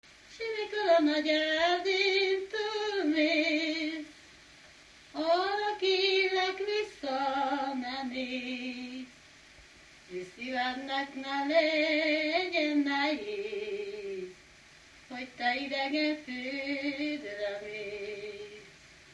Erdély - Csík vm. - Gyimesfelsőlok
Műfaj: Keserves
Stílus: 1.1. Ereszkedő kvintváltó pentaton dallamok
Szótagszám: 8.8.8.8
Kadencia: 5 (b3) 1 1